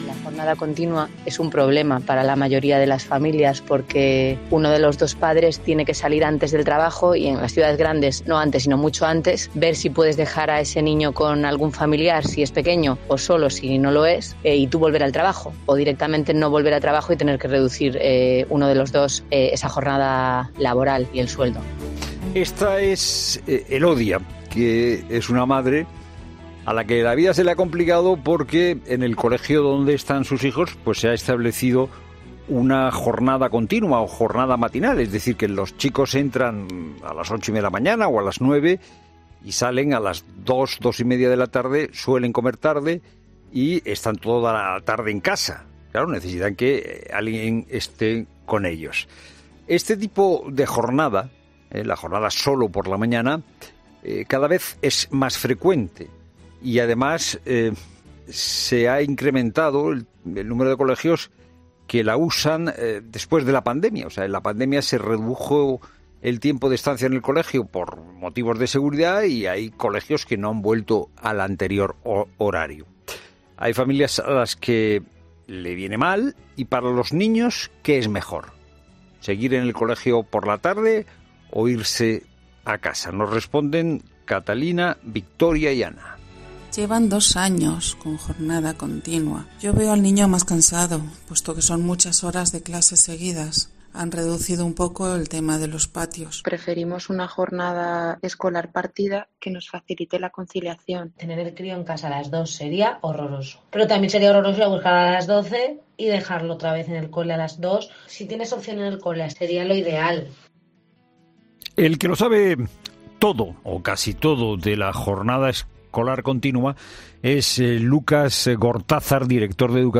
Sobre este asunto han hablado en 'La Tarde', a través de diferentes madres que tienen a sus hijos en este tipo de horario han dado su opinión.